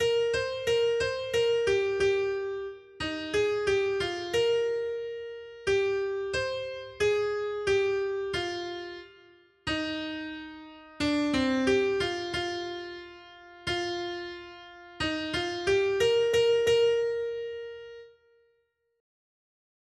Noty Štítky, zpěvníky ol414.pdf responsoriální žalm Žaltář (Olejník) 414 Skrýt akordy R: To je pokolení těch, kdo hledají tvář tvou, Hospodine. 1.